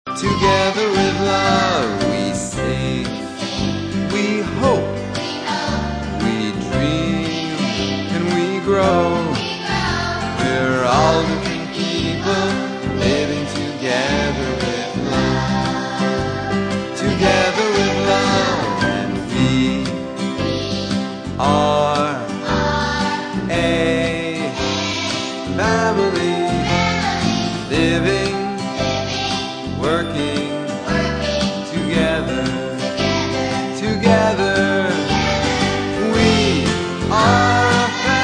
A Call-Response Song About Family